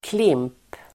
Uttal: [klim:p]